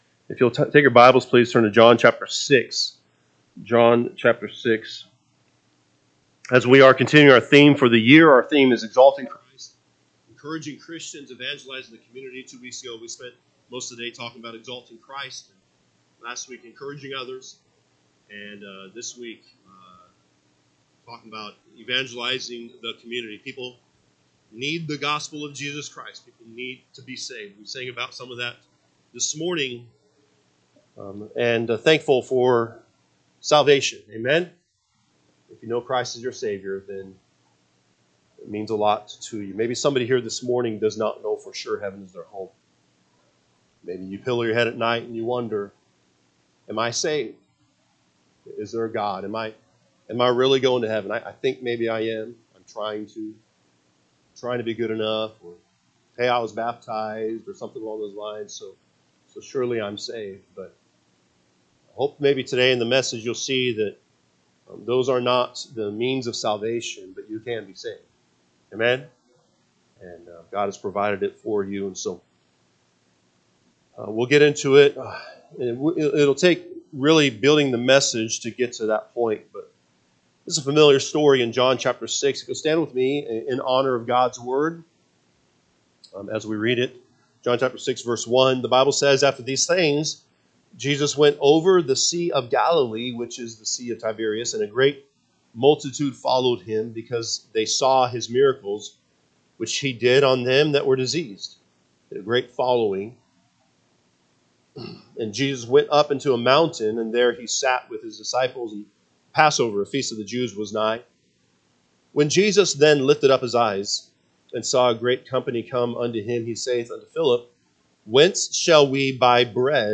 January 26, 2025 am Service John 6:1-15 (KJB) 6 After these things Jesus went over the sea of Galilee, which is the sea of Tiberias. 2 And a great multitude followed him, because they saw…